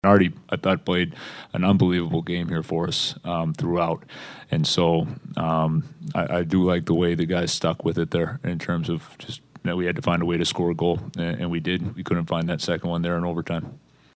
Despite Silovs falling short in the shootout, Muse says the rookie goaltender was the reason the Penguins had a chance to win.